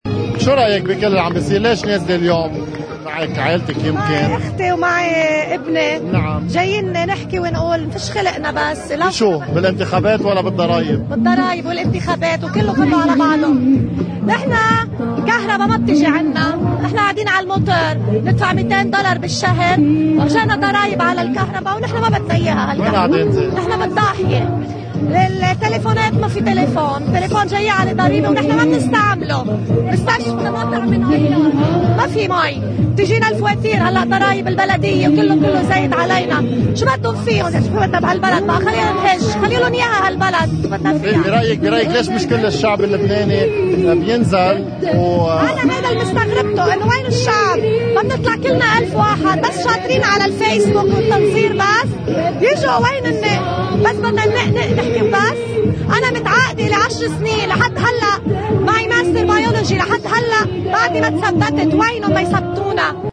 واليكم كلام إحدى اللبنانيات من ساحة الإعتصام